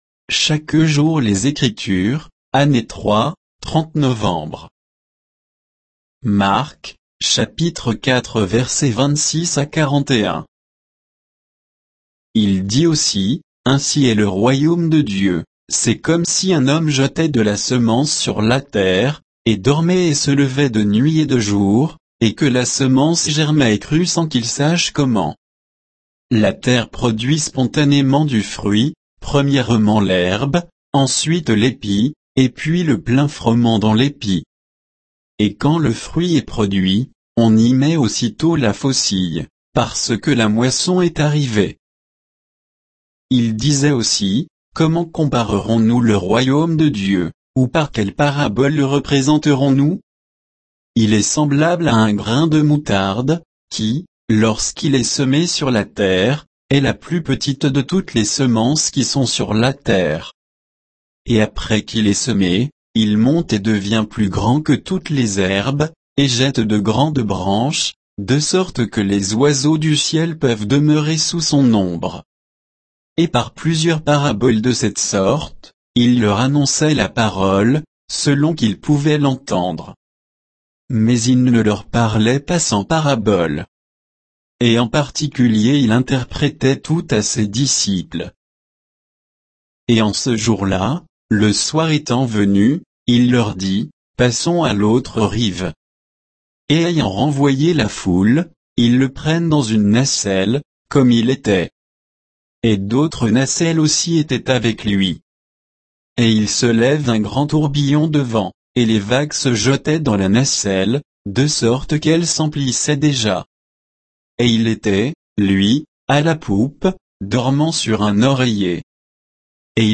Méditation quoditienne de Chaque jour les Écritures sur Marc 4